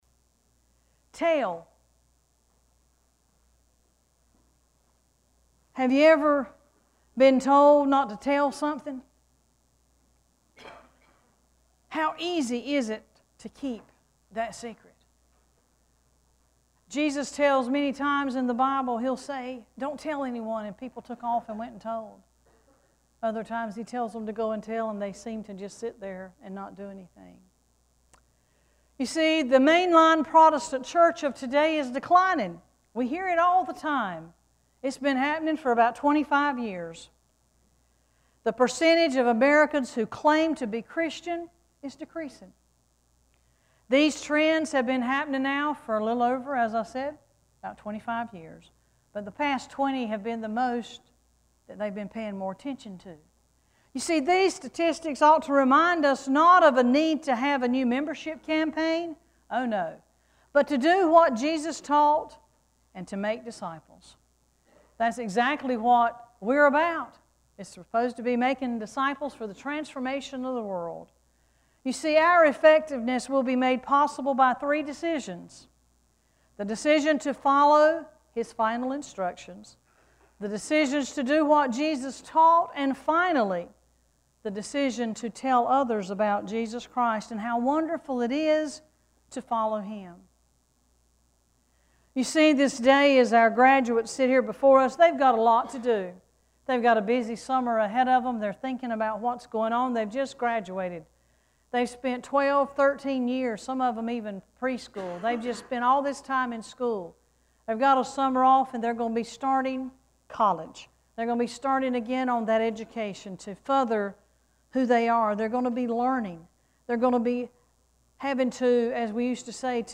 6-14-sermon.mp3